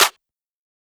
MZ Snareclap [Metro #10].wav